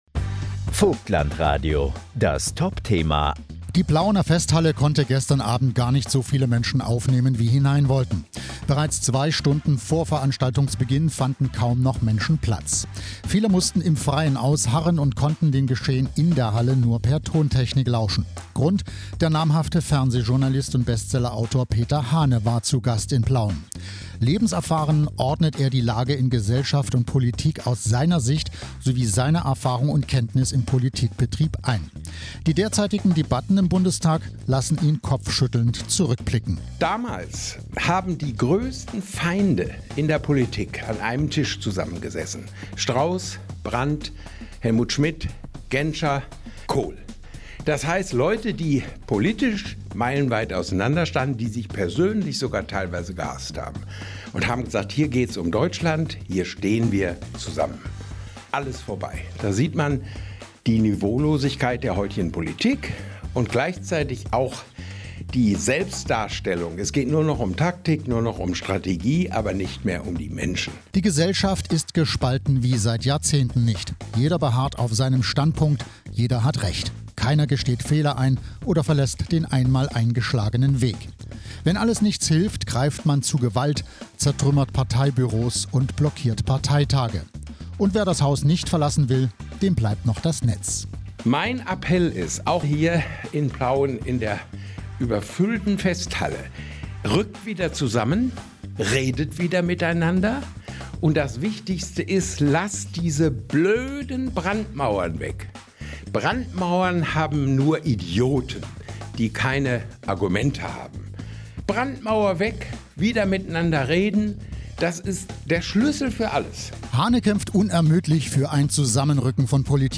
Ein Beitrag vom VOGTLAND RADIO nach der Veranstaltung mit Peter Hahne: